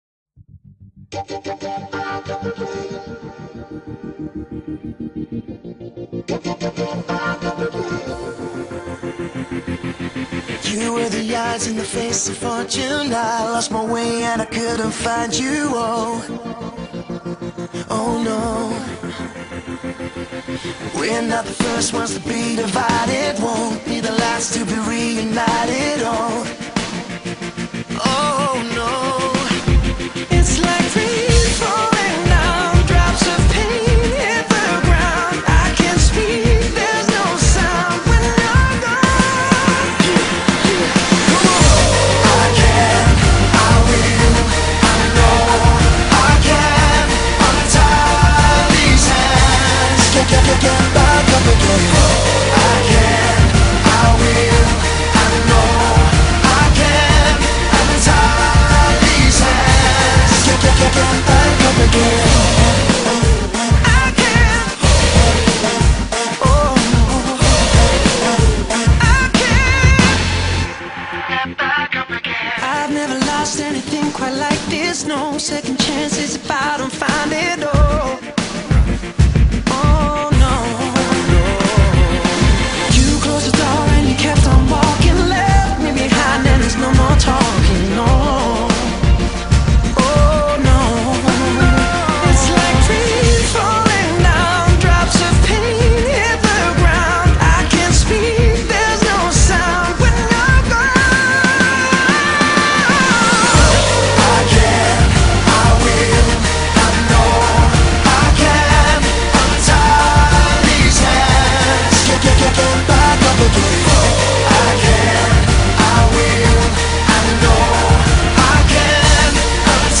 Genre: Dance, Club